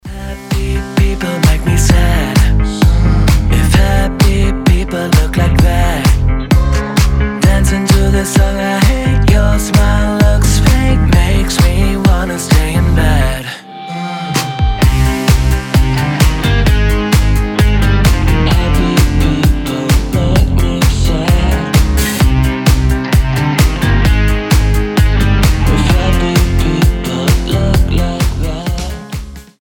• Качество: 320, Stereo
ритмичные
alternative